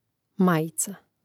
mȁjica majica